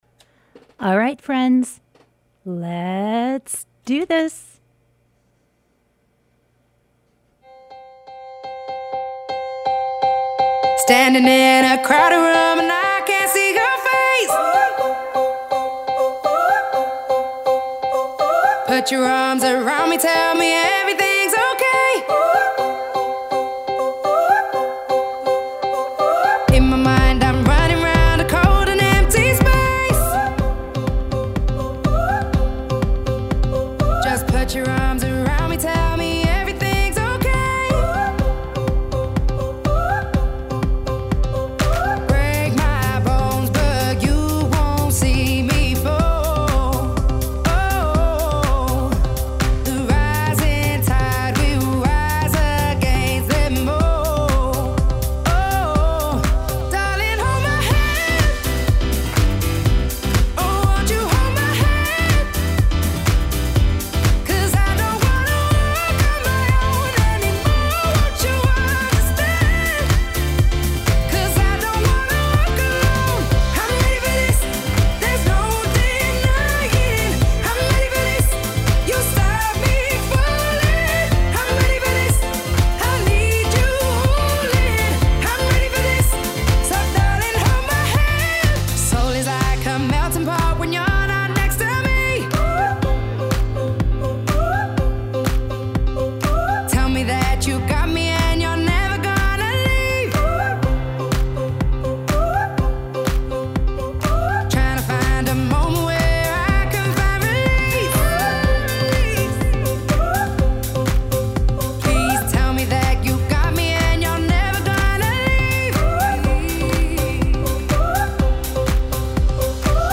Broadcast live every Thursday evening from 6:30 to 8pm on WTBR